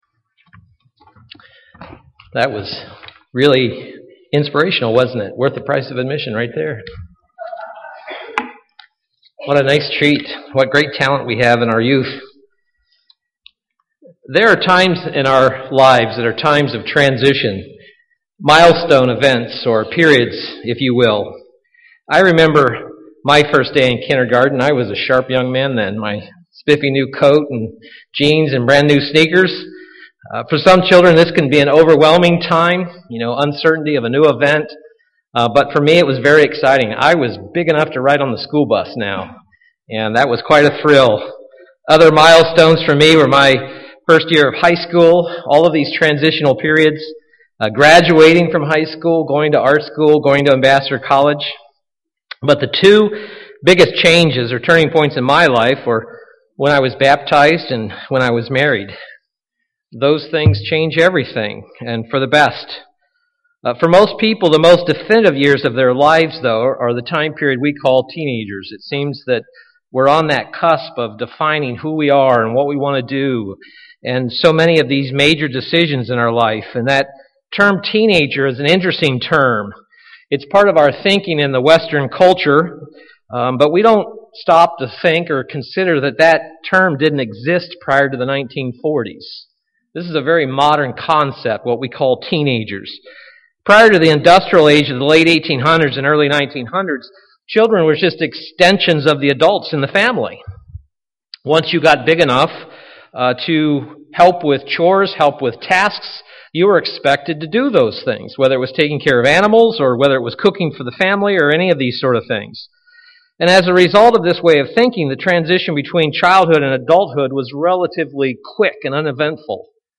UCG Sermon Notes There are times in our lives that are times of transition – milestone events or periods.